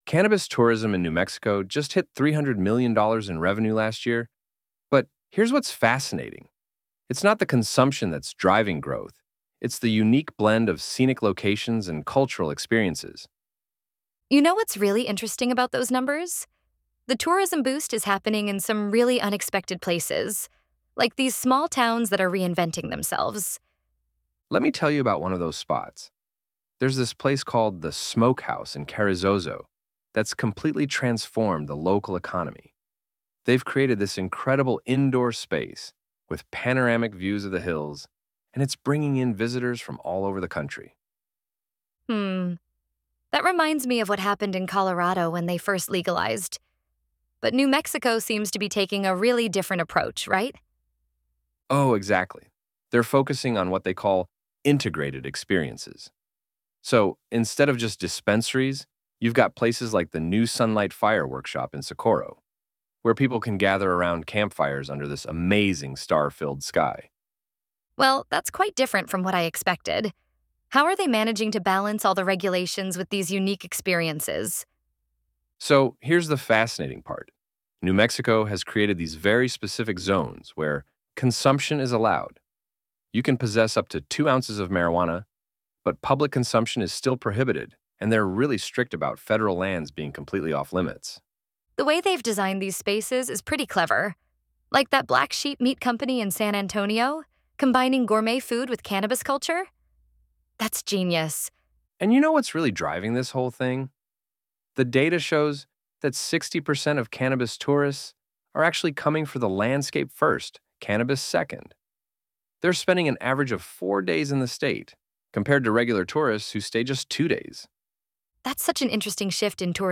From the awe-inspiring beauty of White Sands National Park to the mystical vibes of Taos and the dramatic cliffs of Santa Fe National Forest, our hosts share their top picks for unforgettable cannabis-friendly escapes—always in full compliance with New Mexico’s consumption laws.